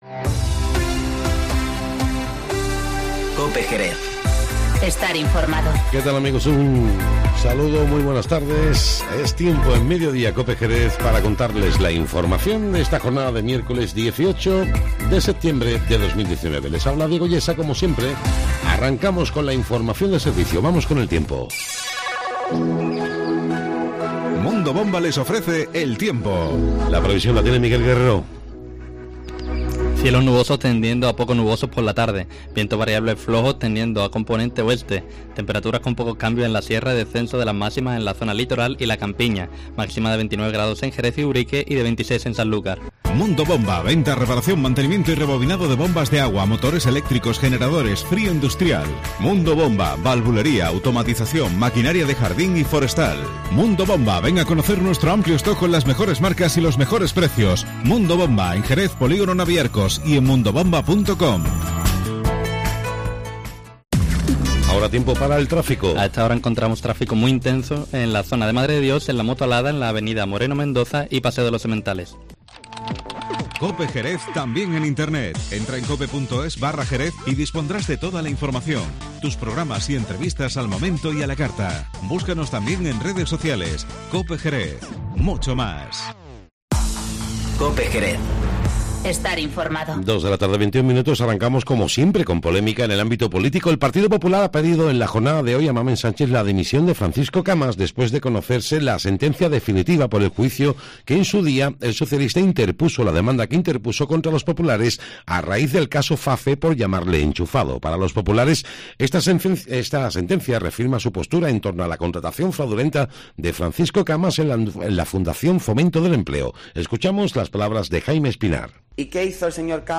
Informativo Mediodía en COPE Jerez- 18-09-19